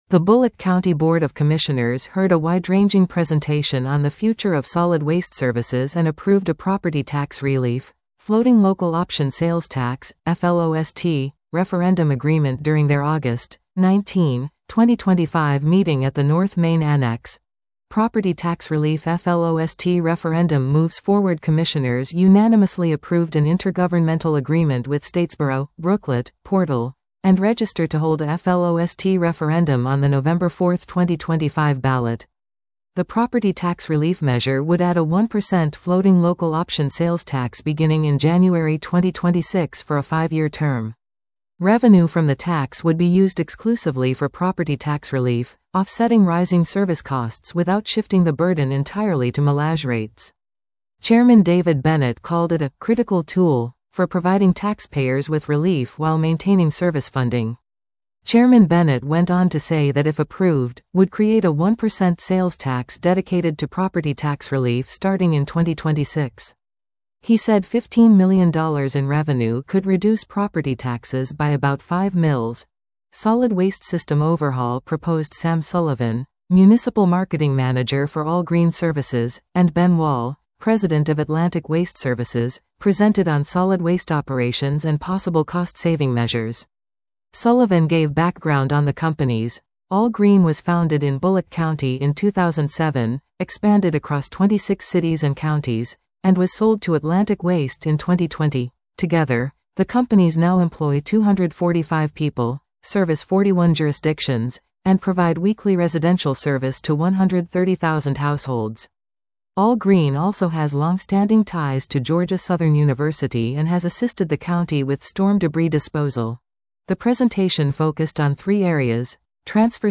The Bulloch County Board of Commissioners heard a wide-ranging presentation on the future of solid waste services and approved a property tax releif - Floating Local Option Sales Tax (FLOST) referendum agreement during their August, 19, 2025 meeting at the North Main Annex.